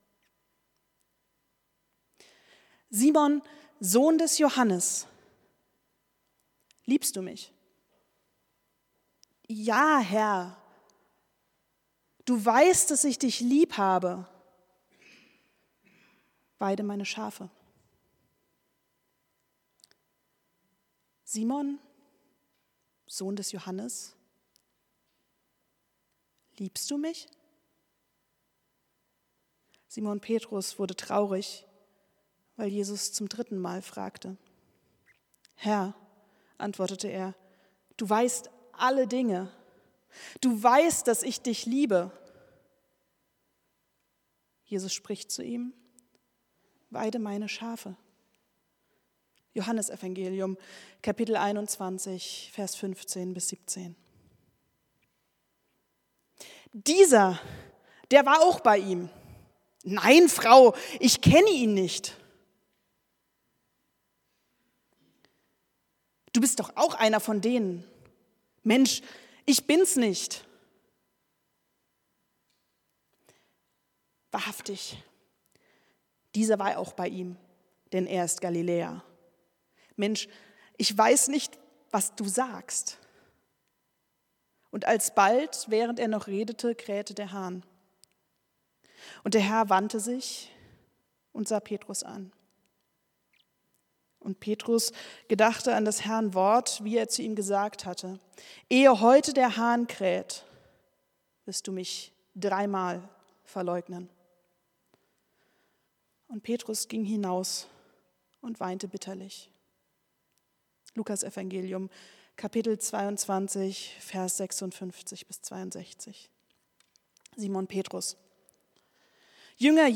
Predigt vom 15.02.2026